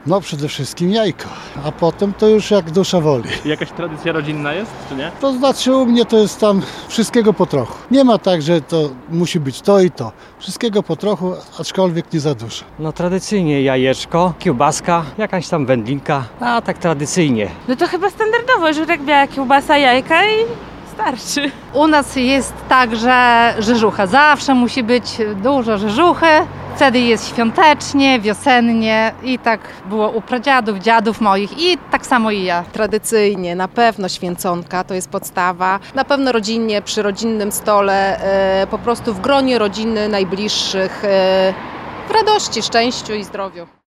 Pytani o świąteczne potrawy mieszkańcy regionu w większości są zgodni. Jak mówili reporterowi Radia 5 – świąteczny stół będą przygotowywać w oparciu o tradycję.
sonda-co-święcą.mp3